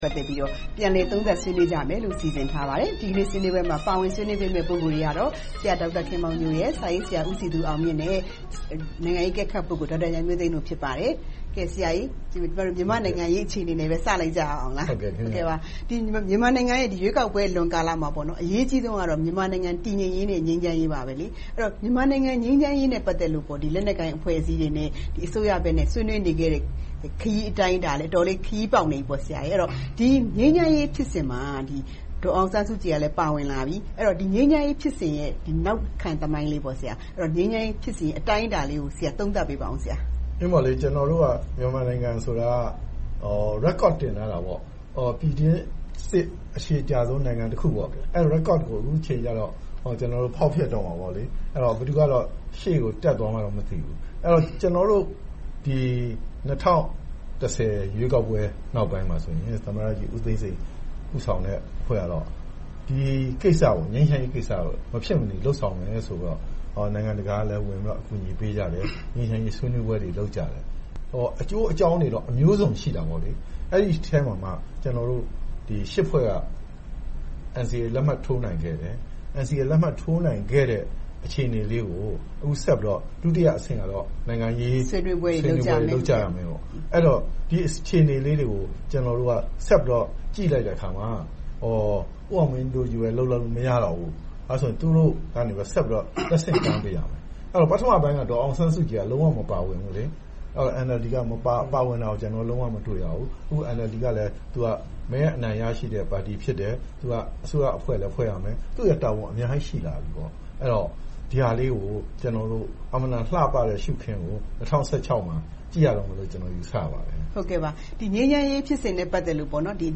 ၂၀၁၅ နိုင်ငံရေးသုံးသပ်ချက် စကားဝိုင်း